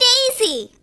One of Princess Daisy's voice clips in Mario Kart: Double Dash!!